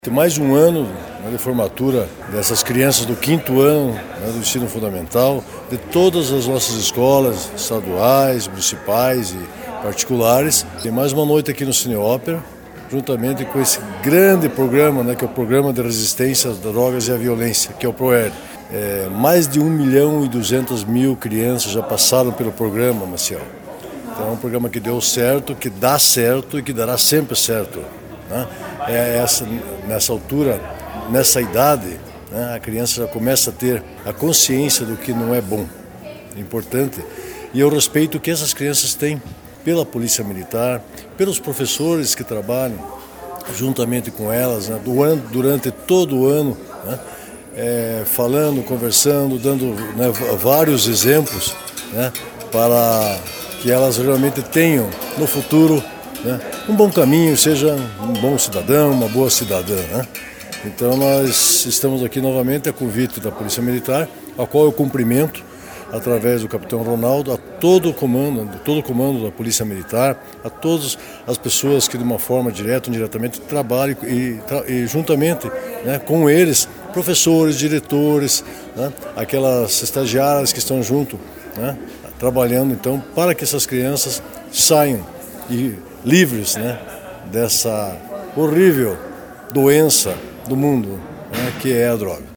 Mais 208 crianças da cidade de Porto União, disseram “não”, as drogas e a violência na noite desta quarta-feira, 9 de dezembro, no Cine Teatro Ópera.
O secretário Municipal da Educação de Porto União Bento Trindade Junior ressaltou a importância do trabalho da Polícia Militar de Porto União, nas escolas da cidade e parabenizou este importante trabalho feito pelo Proerd.